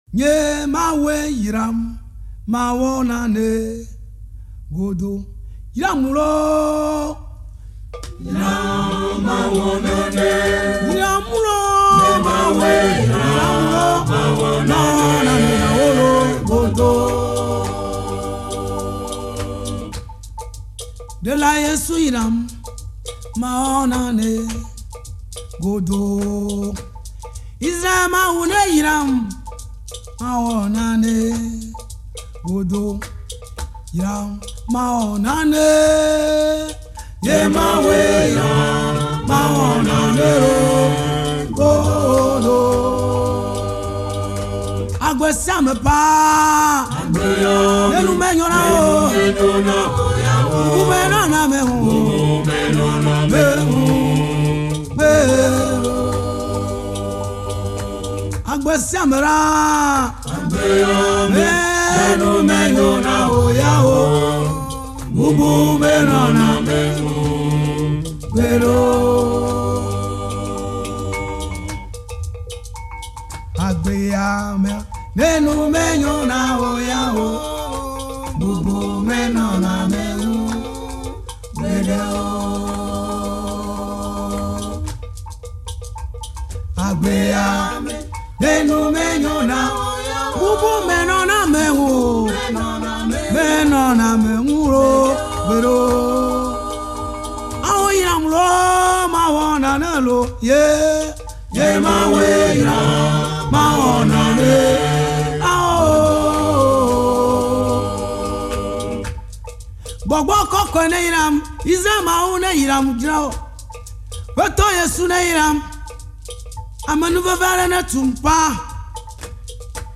adoration